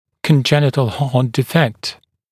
[kən’ʤenɪtl hɑːt dɪ’fekt] [‘diːfekt][кэн’дженитл ха:т ди’фэкт] [‘ди:фэкт]врожденный порок сердца